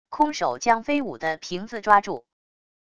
空手将飞舞的瓶子抓住wav音频